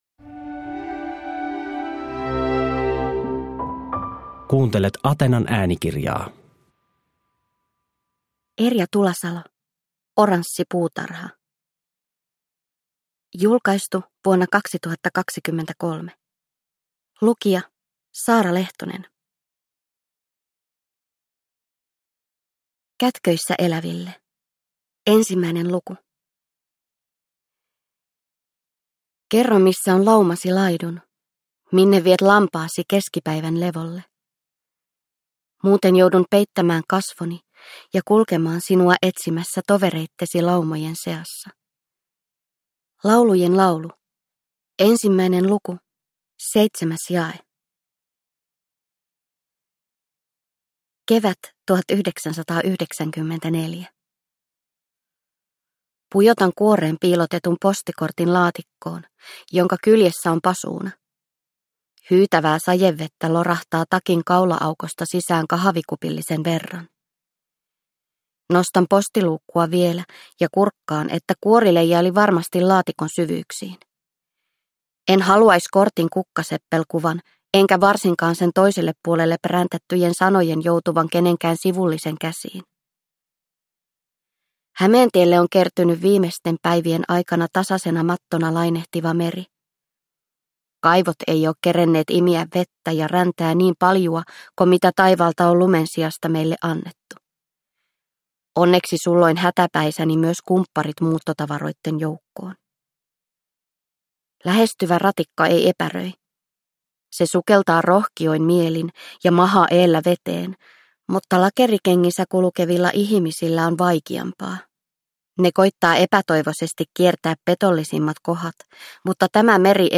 Oranssi puutarha – Ljudbok – Laddas ner